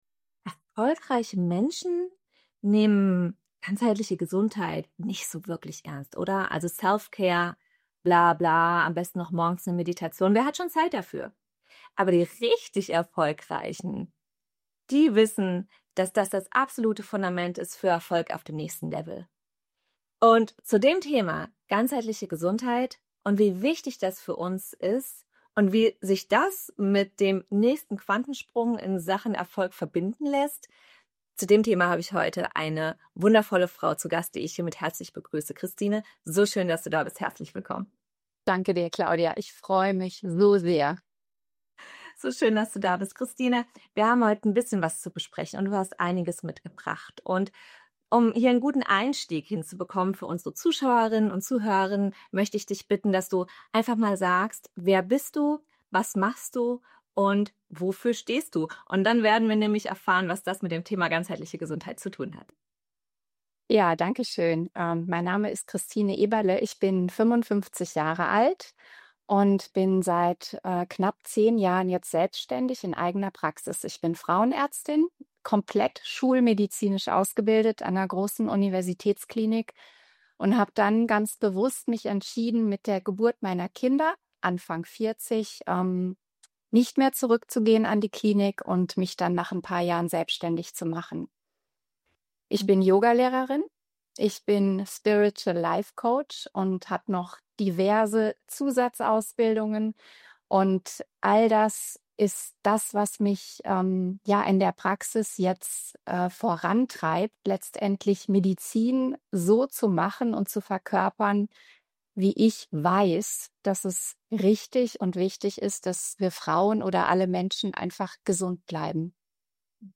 Wir sprechen darüber, was ganzheitliche Gesundheit wirklich bedeutet, warum so viel Heilungspotenzial bereits in uns liegt und wie innere Balance, Klarheit und Körperbewusstsein die Basis für Erfolg auf dem nächsten Level sind. Ein ehrliches, klares Gespräch über Gesundheit, Selbstverantwortung und Führung von innen nach außen – für leistungsstarke Frauen, die keine schlechten Kompromisse mehr machen wollen.